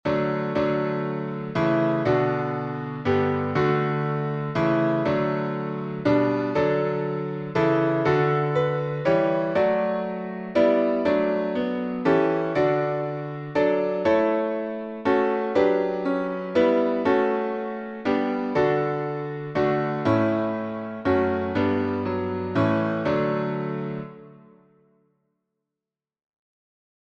Key signature: D major (2 sharps) Time signature: 3/4